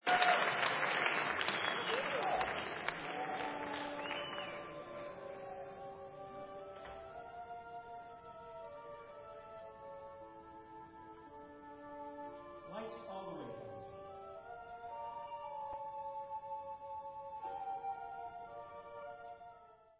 Recorded live november 1994